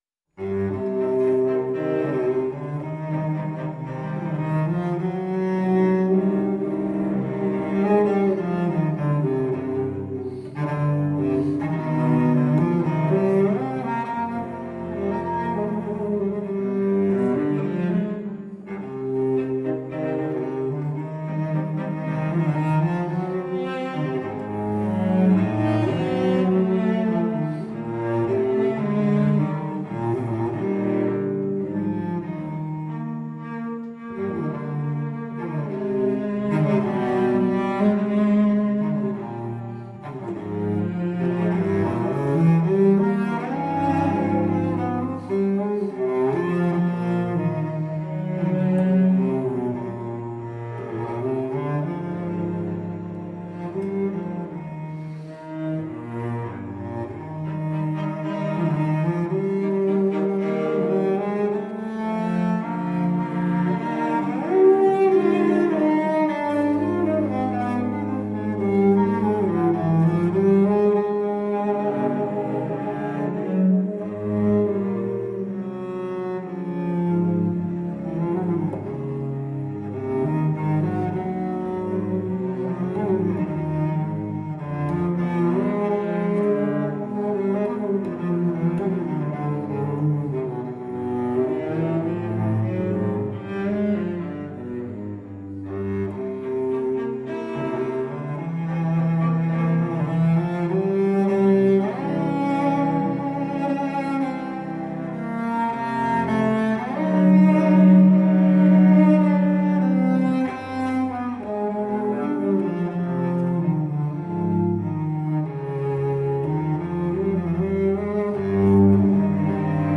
Classical, Romantic Era, Instrumental Classical, Cello